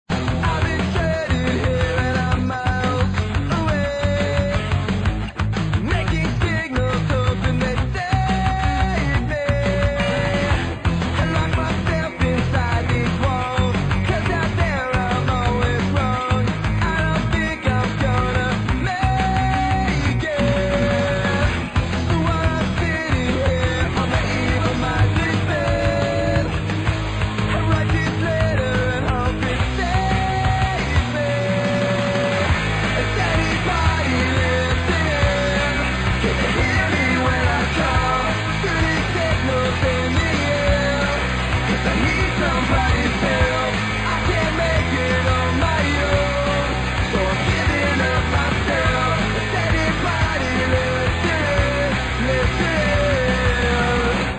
Главная страница>>Скачать mp3>>Рок рингтоны